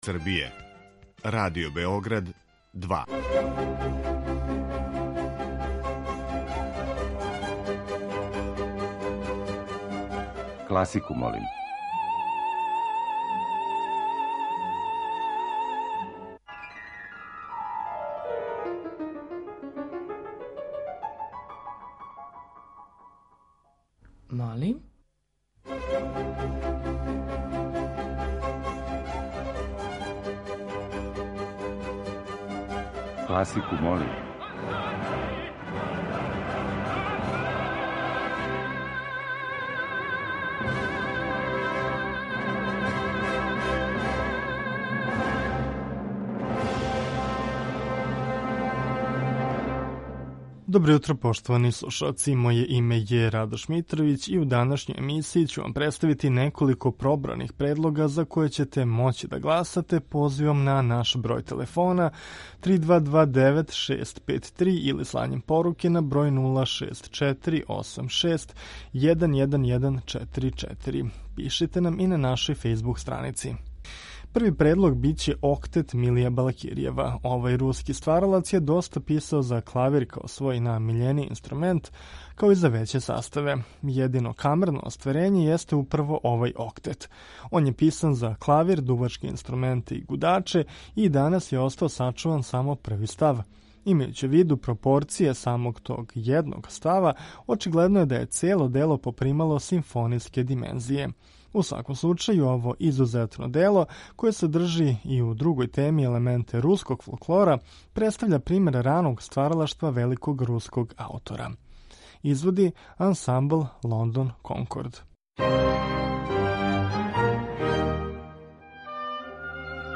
У овонедељном циклусу емисија Класику, молим, тематска окосница биће балетска музика.